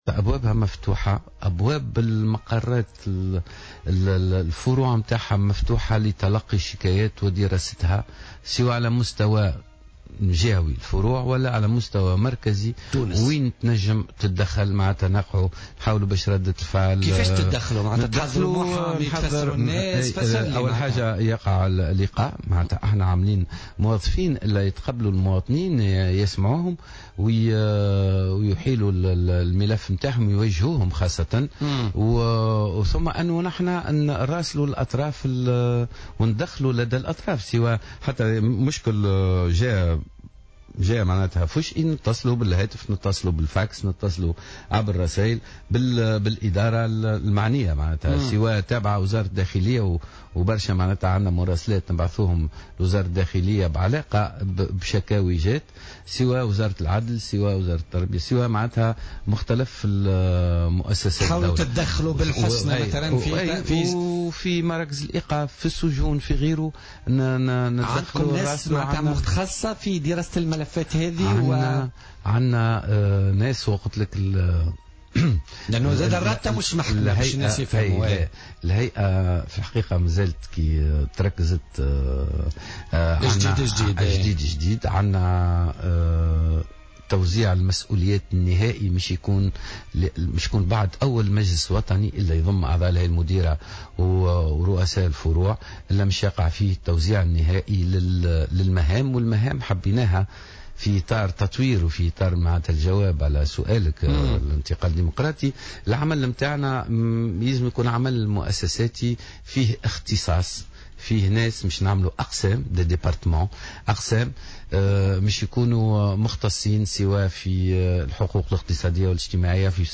وأضاف في مداخلة له في برنامج "بوليتيكا" اليوم أنه يقع الاطلاع على الشكايات الواردة على الرابطة ثم التدخل لدى الأطراف المعنية، إضافة إلى القيام بزيارات ميدانية للسجون ومراكز الإيقاف.